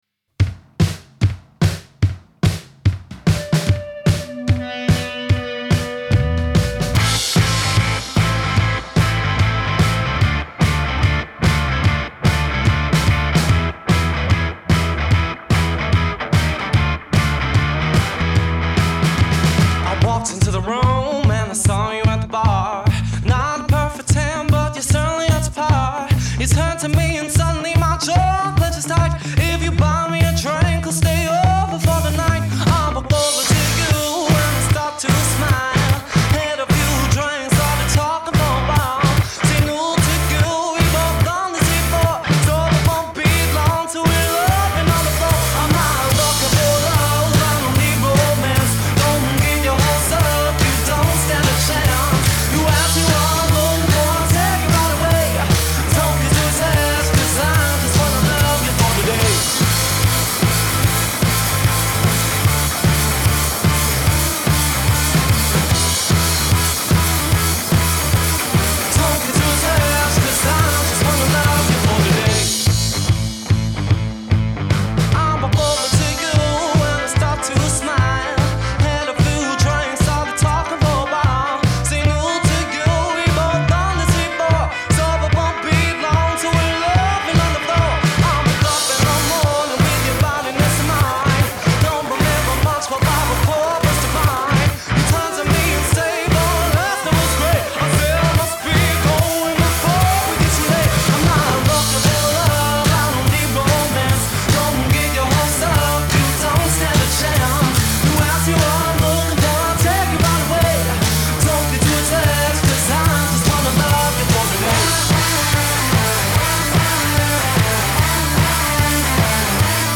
Genre: Rock 'n' Roll, Soul